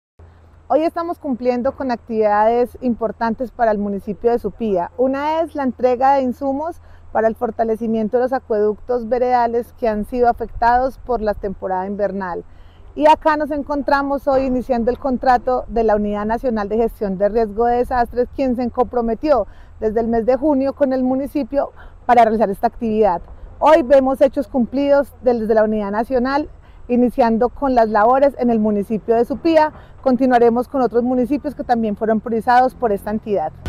Paula Marcela Villamil Rendón, jefe de Gestión del Riesgo de Caldas.